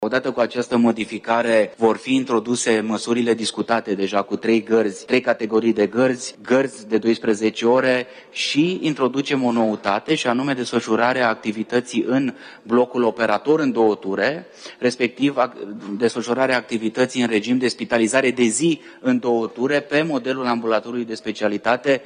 Alexandru Rogobete a vorbit despre cele mai recente modificări în comisiile reunite de buget și finanțe